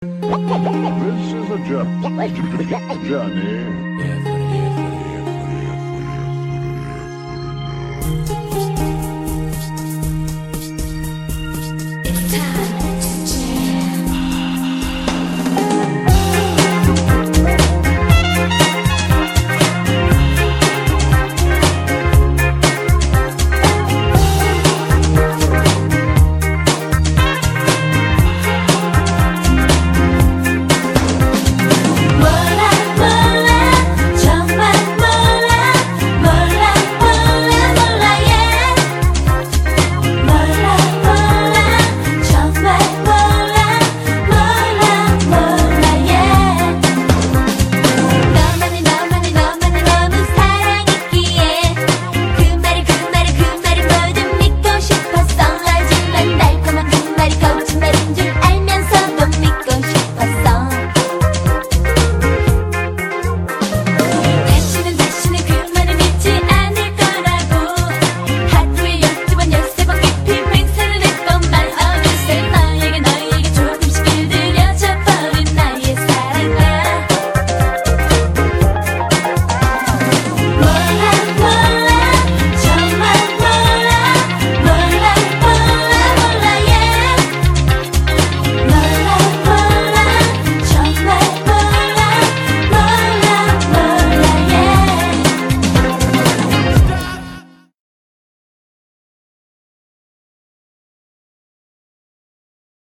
BPM119--1
Audio QualityPerfect (High Quality)